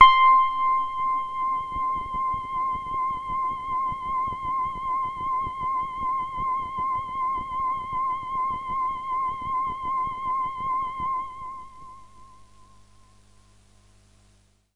描述：这是我的Q Rack硬件合成器的一个样本。
低通滤波器使声音变得圆润而柔和。
在较高的区域，声音变得非常柔和，在归一化之后，一些噪音变得很明显。
Tag: 低音 电子 醇厚 多样品 柔软 合成器 华尔